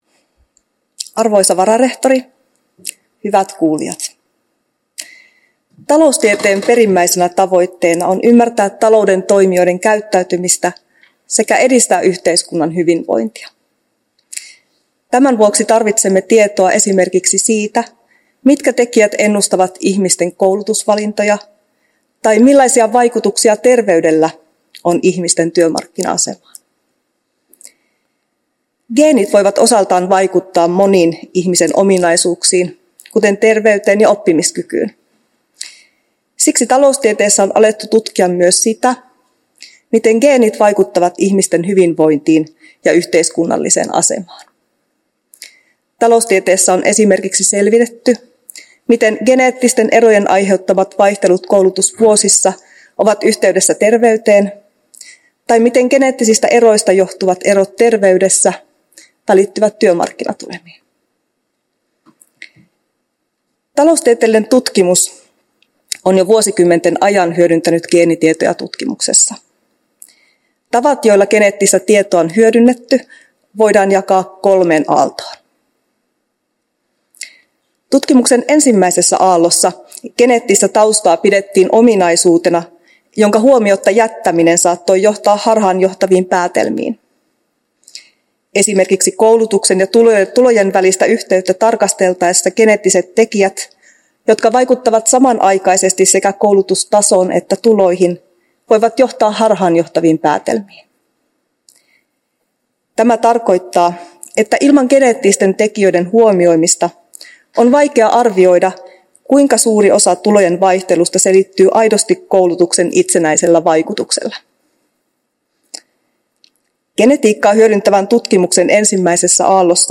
Uusien professoreiden juhlaluennot 10.12.2024